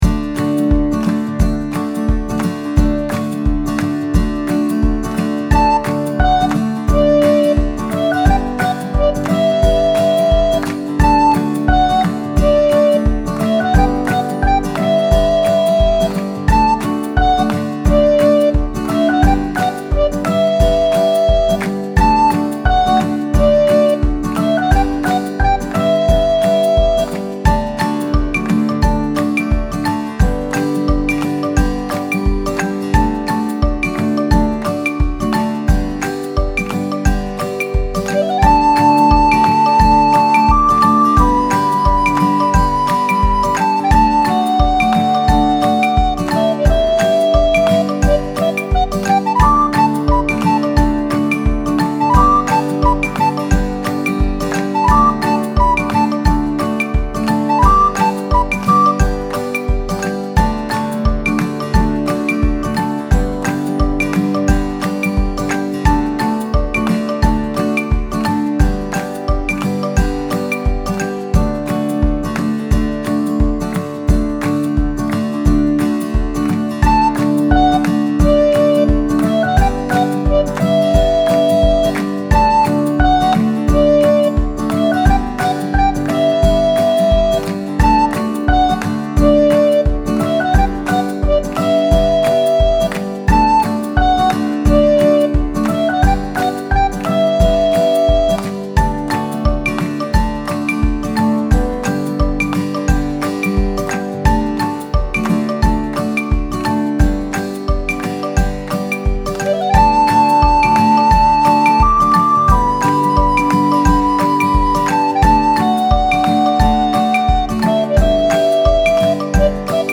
アコギとリコーダーが奏でる爽やかで明るい曲。 楽しげな雰囲気でピクニックやドライブ、キッズ系の動画とかにも合いそう。